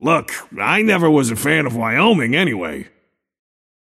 Shopkeeper voice line - Look, I never was a fan of Wyoming anyway.